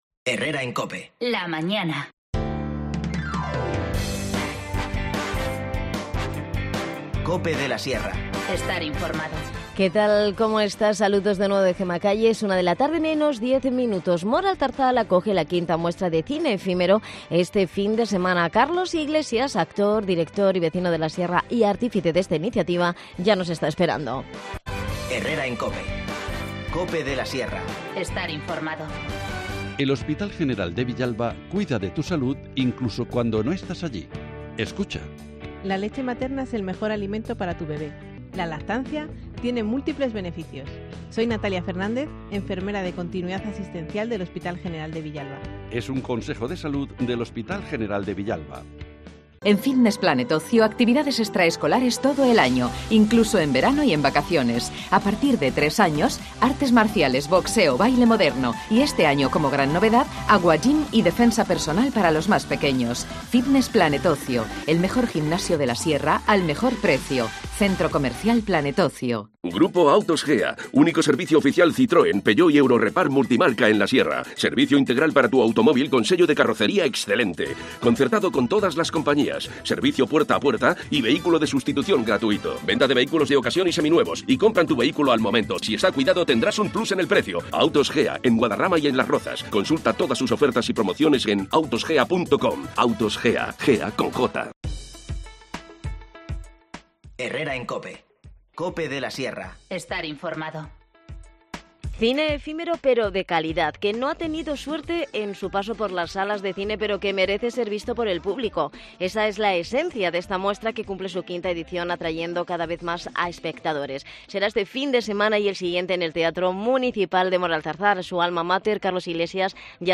Moralzarzal acoge desde del 14 al 22 de marzo la V Edición de la Muestra de Cine Efímero. Hablamos con Carlos Iglesias, actor, director y artífice de esta iniciativa